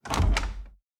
Doors Gates and Chests
Chest Close 1.wav